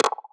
Perc Funk 5.wav